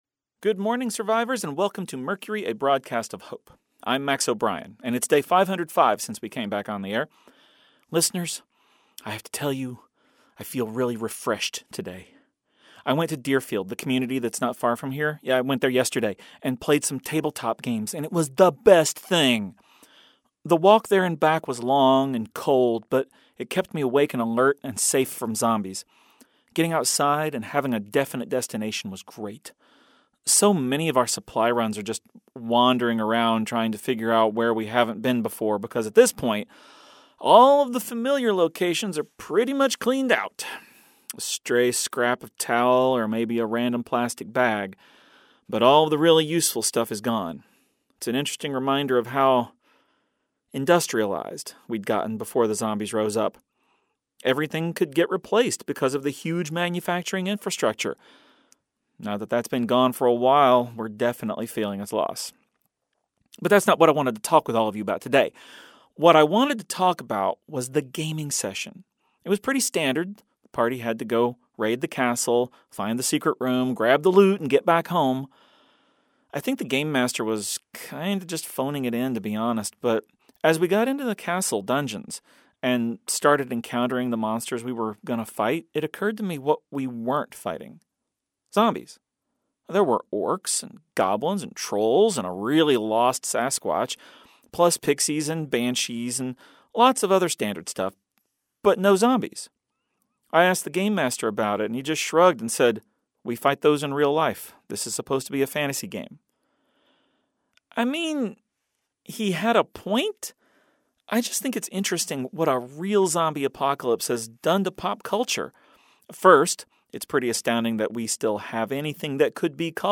A daily audio theatre experience that tells the story of a small group of people living in a college radio station broadcasting during the zombie apocalypse. Rather than focusing on the horror and violence that is typical of the zombie genre, Mercury looks at it through the lens of trying to find hope and survival in a world where hope is scarce and survival is difficult.